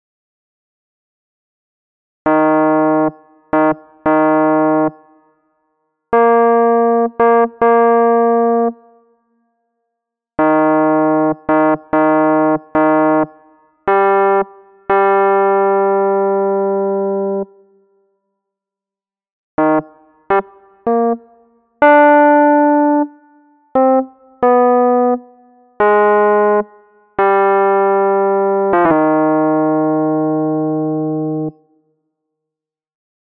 Key written in: E♭ Major
Each recording below is single part only.
Spiritual
Learning tracks sung by